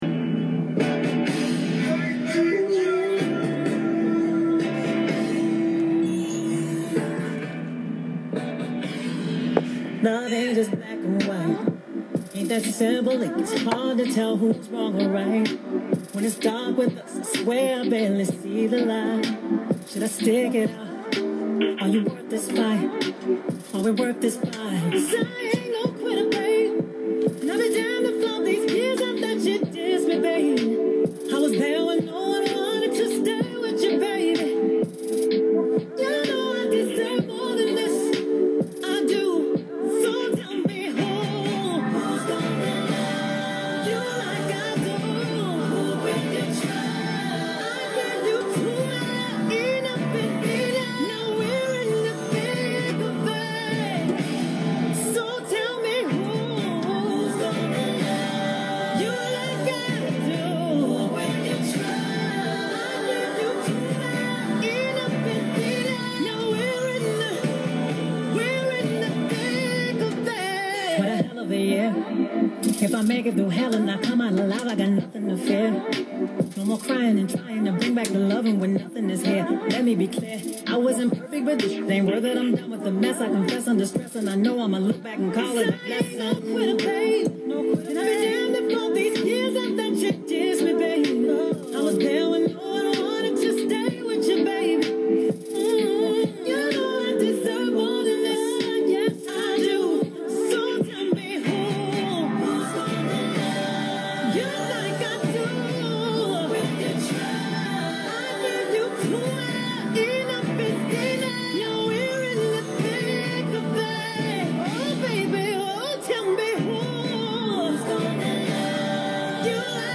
WRBO radio interview
It is about a half hour in length but begins with 3-5 minutes of music - feel free to skip ahead to the interview.